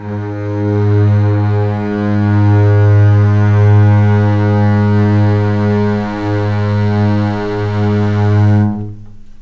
G#2 LEG MF L.wav